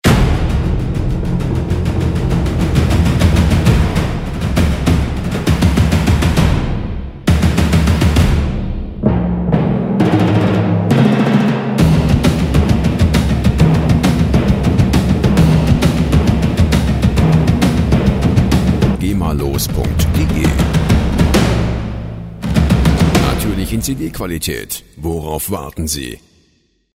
Timpani
Der Klang der Musikinstrumente
Instrument: Pauke
Tempo: 134 bpm
timpani.mp3